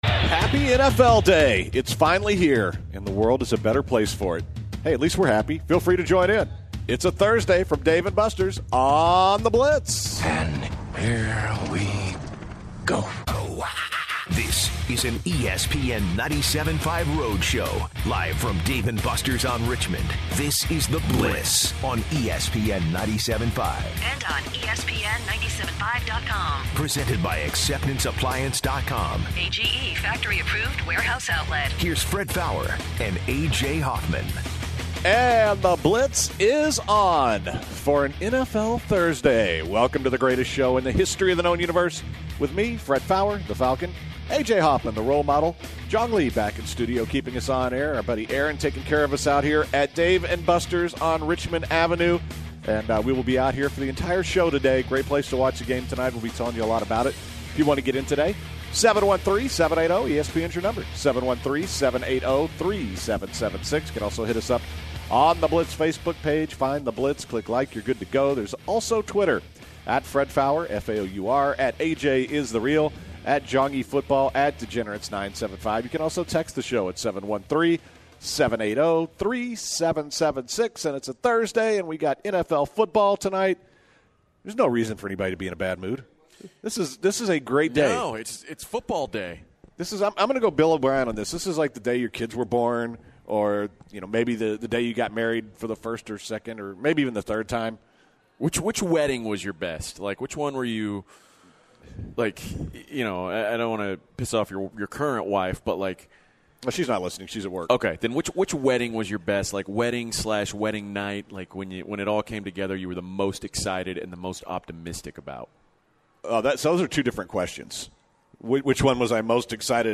Live from Dave & Buster's, the guys discuss NFL contract extensions,-specifically why the Bengals should extend A.J. Green. Also, they talk about deceiving fantasy football picks, snot buckets, and Charlie Strong.